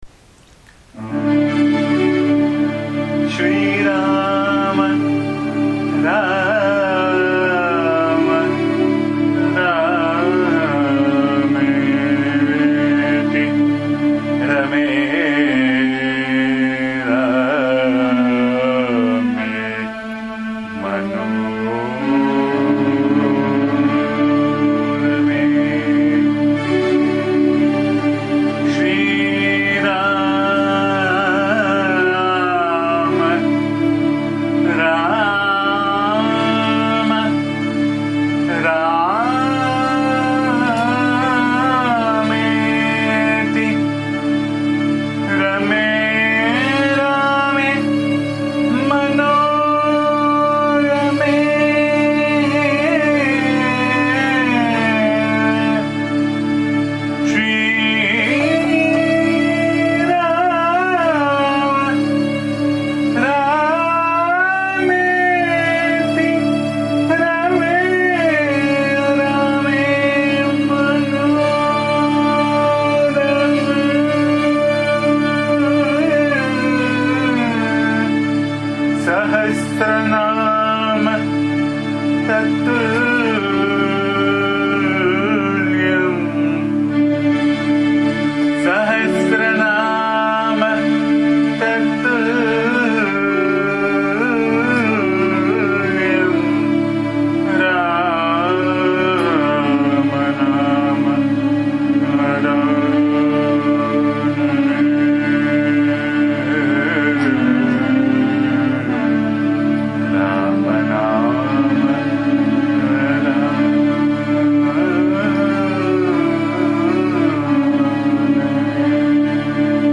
bhajan song